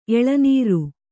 ♪ eḷanīru